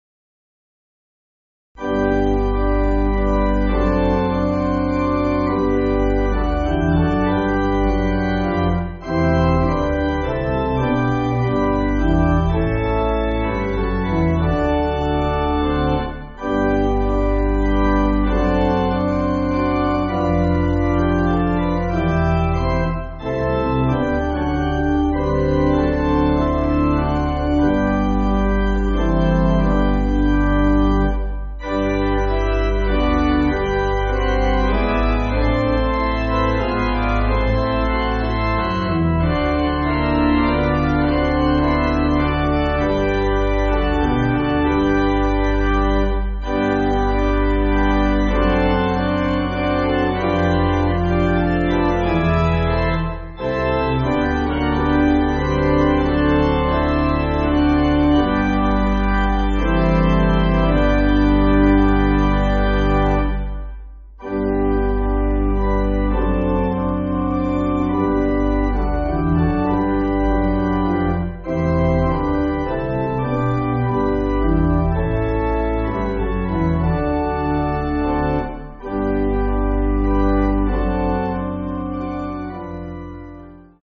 (CM)   3/G